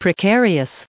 added pronounciation and merriam webster audio
609_precarious.ogg